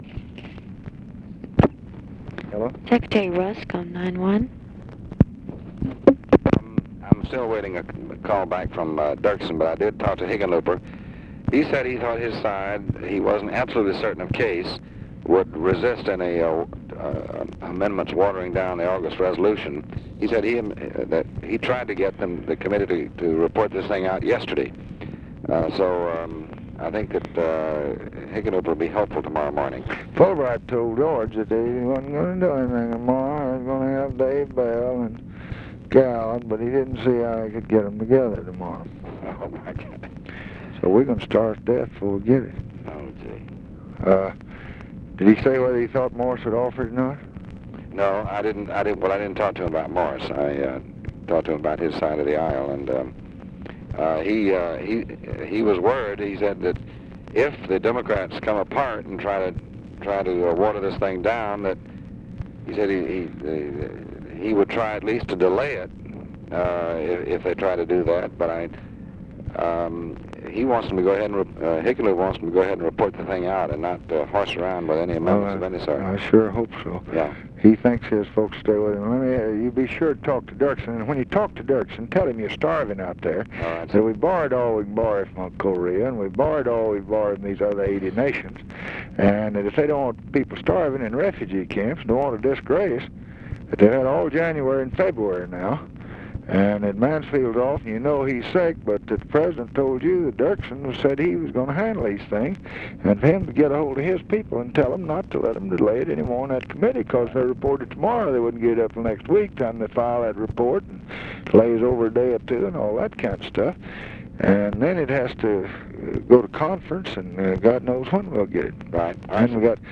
Conversation with DEAN RUSK and CORNELIUS GALLAGHER, February 22, 1966
Secret White House Tapes